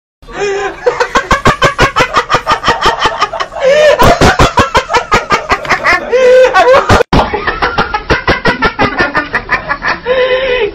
Âm thanh "Thầy Giáo Ba Cười" | Hiệu ứng âm thanh độc lạ ghép và chỉnh sửa video